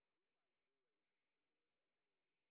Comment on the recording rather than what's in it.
sp07_train_snr0.wav